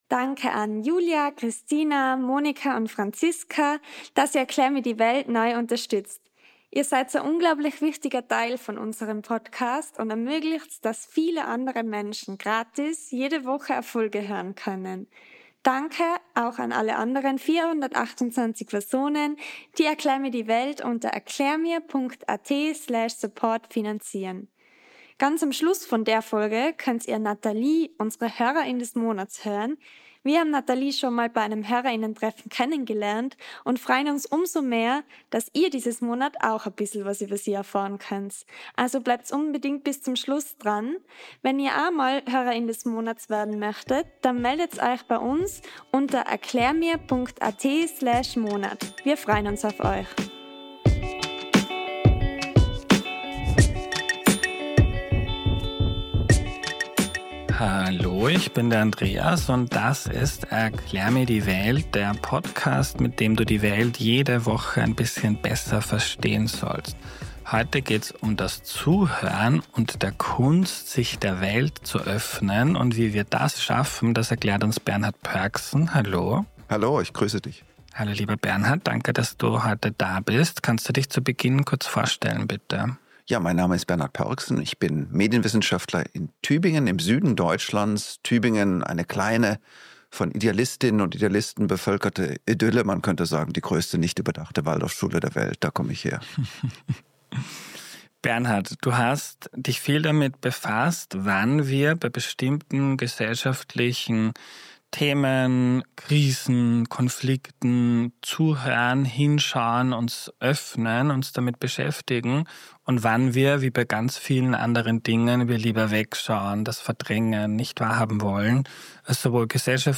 Ein Gespräch über das Hinschauen und Wegschauen.